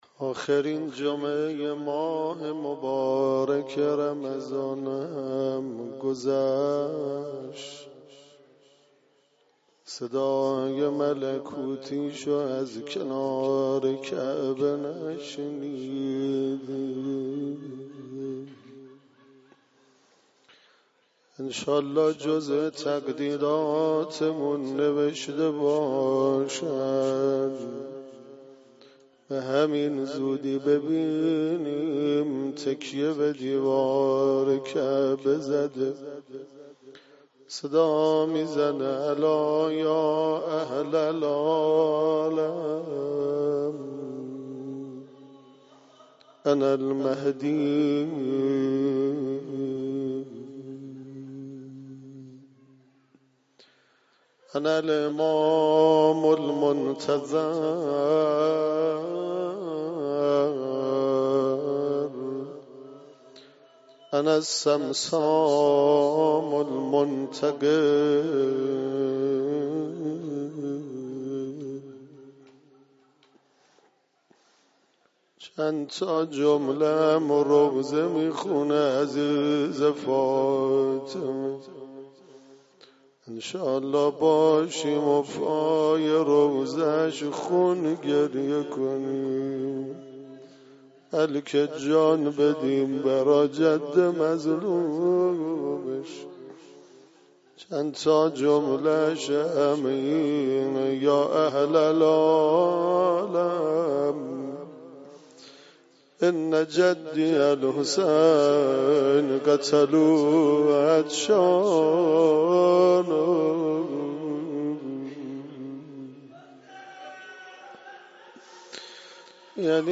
روضه امام حسین علیه السلام